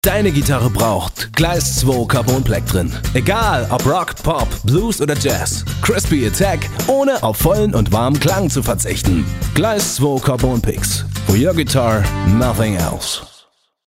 ein Junger dynamischer und frischer Sprecher - gesanglich und spielerisch ausgebildet.
Kein Dialekt
Sprechprobe: Werbung (Muttersprache):